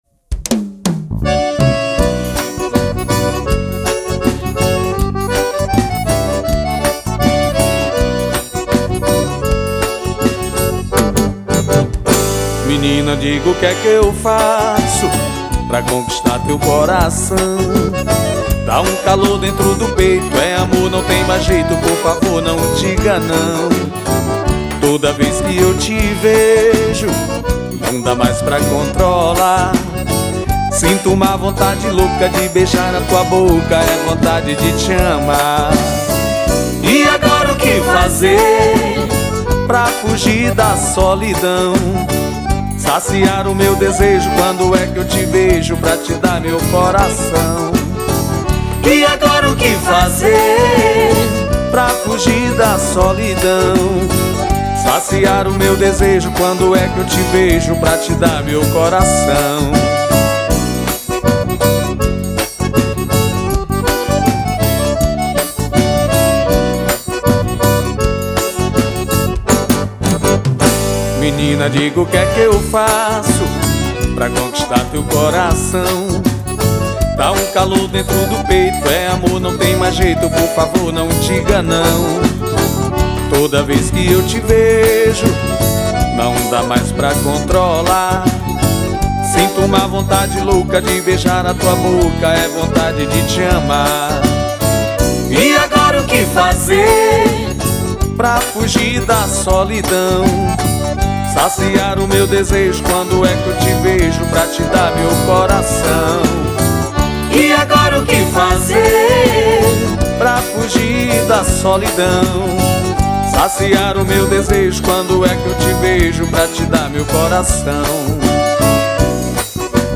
2528   02:45:00   Faixa: 7    Baião